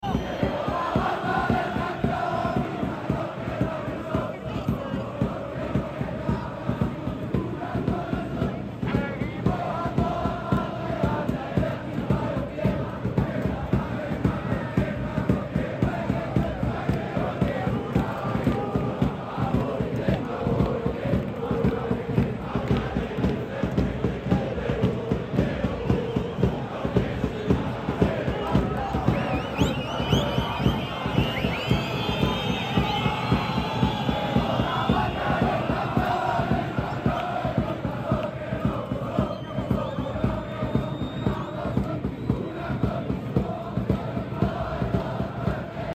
🟢🏟 Así están ya las inmediaciones de La Cartuja a dos horas de que el Real Betis se estrene en su nueva casa. Gran ambiente en la previa.